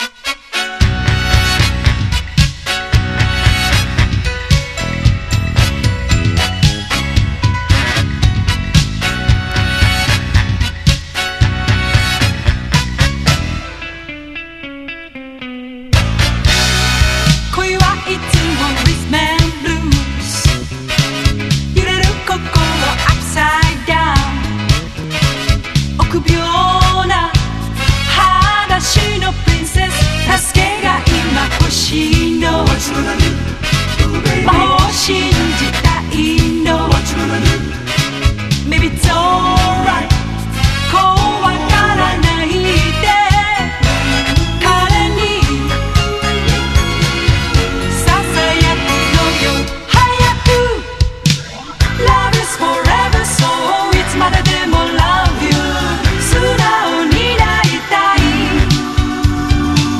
ROCK / 60'S / SURF / HOT ROD / HAWAII / OLDIES